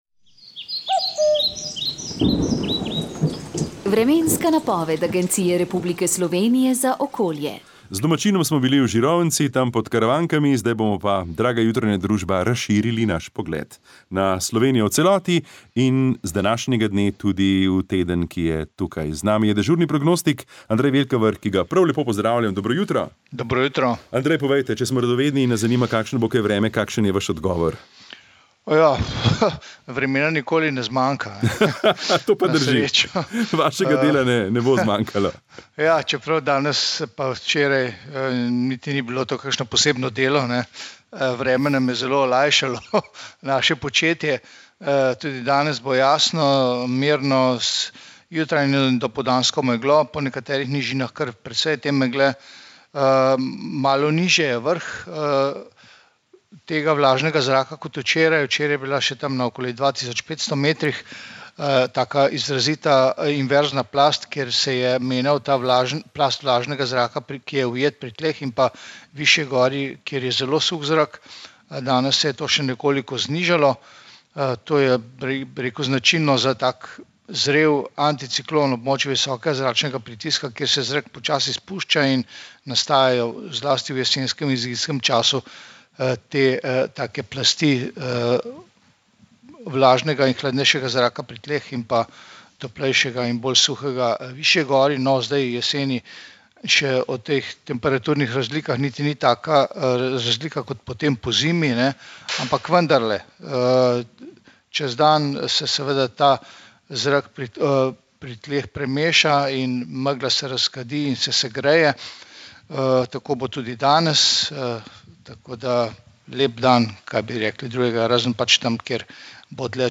Vremenska napoved 05. avgust 2022